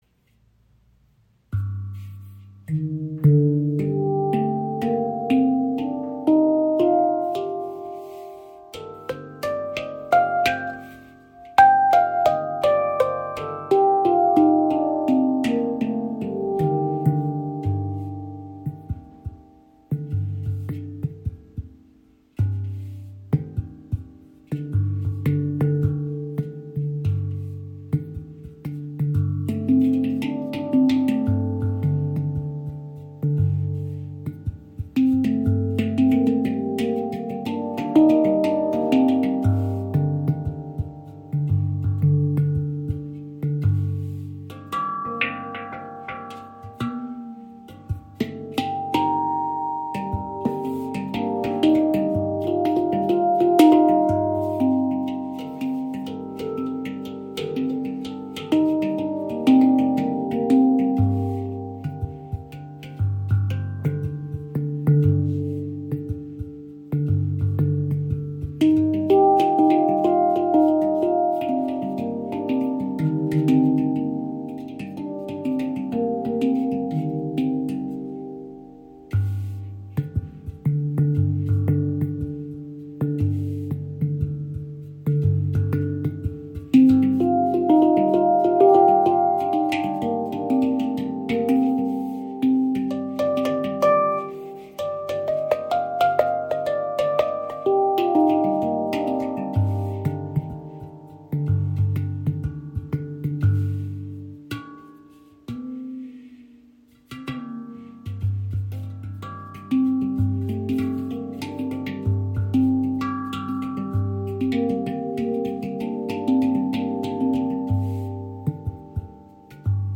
Handpan Meraki | G Minor | 17 Klangfelder – umfangreich & dunkel
• Icon Pflegeleichter Edelstahl mit langem Sustain und tollem Bending
G Minor – exotisch, dunkel und ausdrucksstark
Handgefertigte Edelstahl Meraki-Handpan in G Minor mit sattem Bass und tollen Bending-Möglichkeiten – ideal für Improvisation, meditative Klangreisen.
Dadurch entsteht ein dunkler, ethnischer Charakter, der an Hungarian Minor oder G Minor with Blues Note erinnert und orientalisch klingende Melodien ermöglicht.
Die Stimmung mit den Tönen (G – (D# D) G A A# C D D# F G (A A# D D# F G)) bietet tiefe, tragende Basstöne, klare Mittellagen und helle Höhen, die harmonisch ineinander greifen.